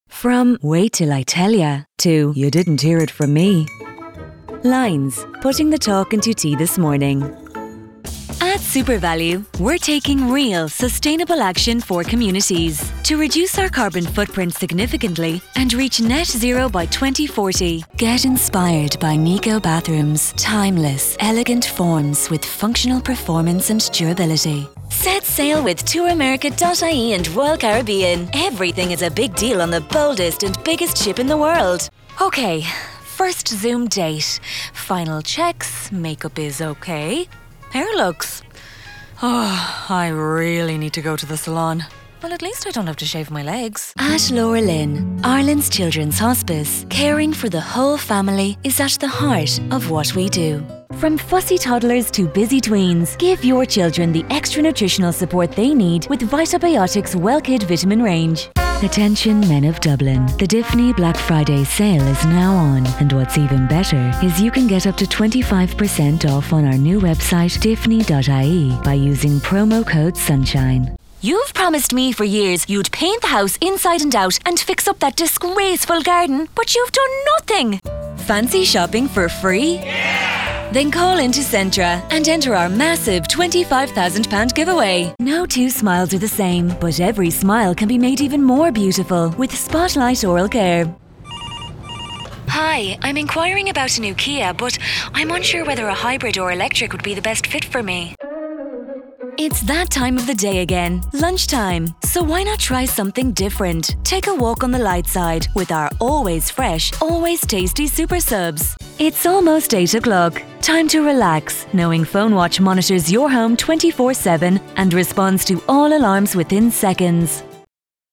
Foreign & British Female Voice Over Artists & Actors
Child (0-12) | Yng Adult (18-29)